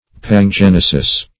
Pangenesis \Pan*gen"e*sis\, n. [Pan- + genesis.] (Biol.)